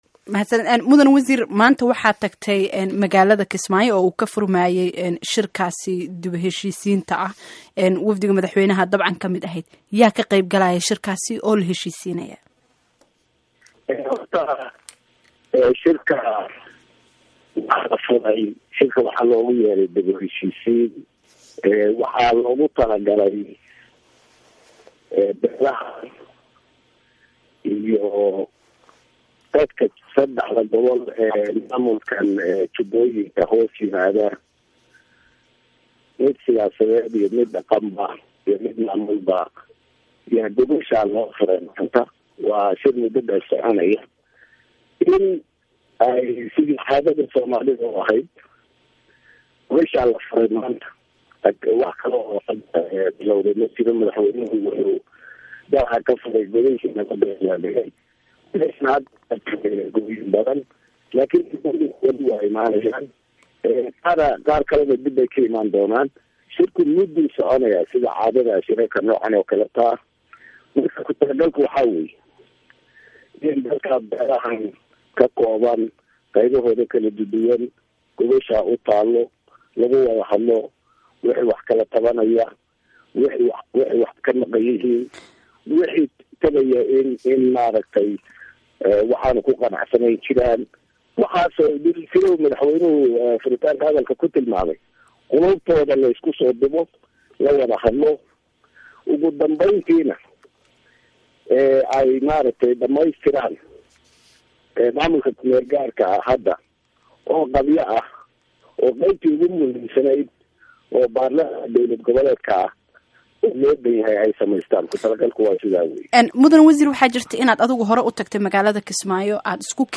Dhageyso Wareysiga Wasiirka Maaliyada Xalane iyo Barre Hiiraale